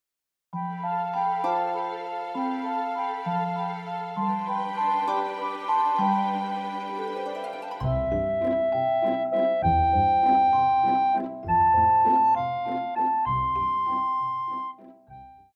古典
高音直笛
樂團
聖誕歌曲,聖歌,教會音樂,古典音樂
獨奏與伴奏
有主奏
有節拍器